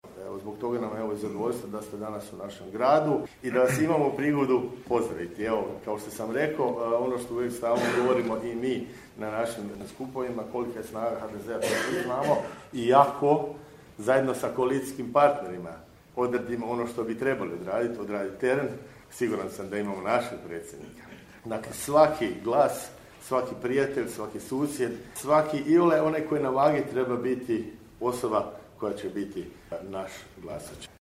Započeo ju je u Garešnici, gdje se sastao s članovima Gradskog odbora stranke i građanima u Centru za posjetitelje. Dobrodošlicu je poželio gradonačelnik Garešnice Josip Bilandžija (HDZ), uvjeren da će imati predsjednika ako dobro odrade posao na terenu, ali uz borbu za svaki glas.